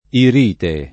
vai all'elenco alfabetico delle voci ingrandisci il carattere 100% rimpicciolisci il carattere stampa invia tramite posta elettronica codividi su Facebook irite [ ir & te ] s. f. («minerale»; «infiammazione dell’iride»)